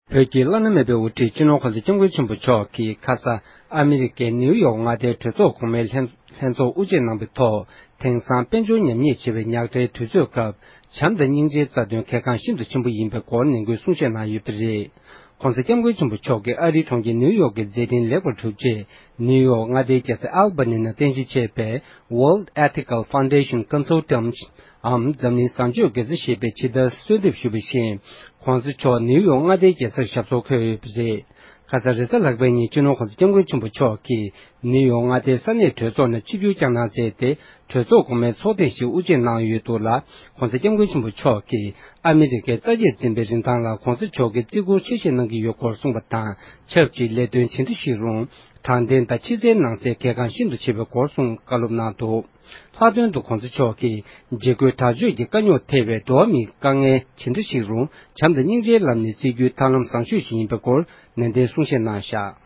སྒྲ་ལྡན་གསར་འགྱུར། སྒྲ་ཕབ་ལེན།
༄༅༎ཕྱི་ཟླ་ལྔ་པའི་ཚེས་དྲུག་རེས་གཟའ་ལྷག་པའི་ཉིན་བོད་ཀྱི་བླ་ན་མེད་པའི་དབུ་ཁྲིད་སྤྱི་ནོར་༸གོང་ས་༸སྐྱབས་མགོན་ཆེན་པོ་མཆོག་ཨ་མེ་རི་ཀའི་གྲོང་ཁྱེར་ནིའུ་ཡོརྐ་གི་གྲོས་ཚོགས་གོང་པའི་ཚོགས་ཁང་ནང་ཆིབས་བསྒྱུར་བསྐྱངས་ནས་གྲོས་ཚོགས་ཀྱི་ལྷན་ཚོགས་དབུ་འབྱེད་གནང་བ་དང་གྲོས་ཚོགས་གོང་མའི་ཚོགས་མི་རྣམས་ལ་བྱམས་དང་སྙིང་རྗེའི་སྐོར་བཀའ་སློབ་གསུང་བཤད་བསྩལ་གནང་མཛད་ཡོད་པ་རེད༎